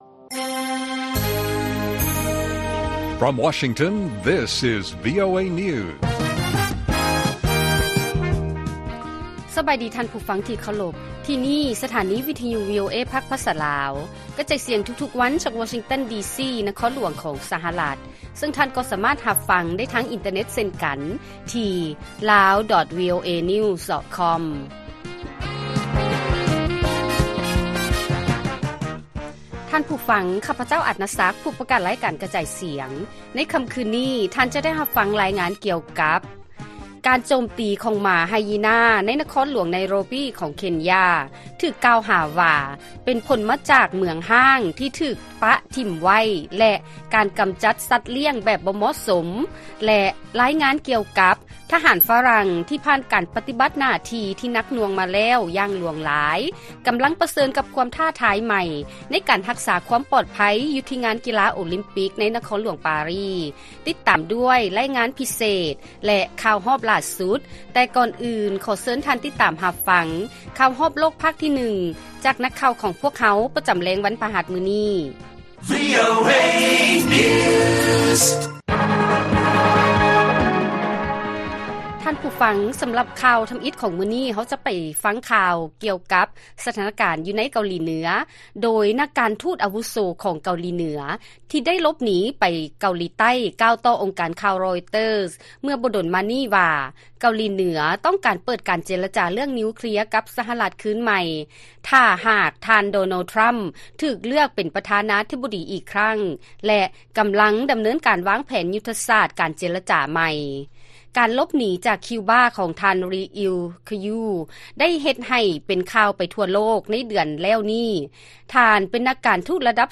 ລາຍການກະຈາຍສຽງຂອງວີໂອເອລາວ: ເກົາຫຼີເໜືອ ຕ້ອງການເປີດການເຈລະຈາເລື້ອງນິວເຄລຍຄືນໃໝ່ ຖ້າທ່ານ ທຣຳ ຊະນະການເລືອກຕັ້ງ ຄືນໃໝ່